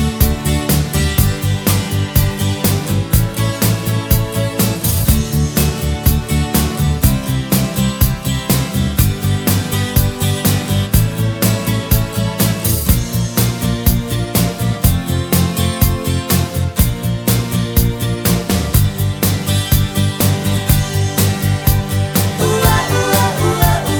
No Saxophone Pop (1980s) 4:26 Buy £1.50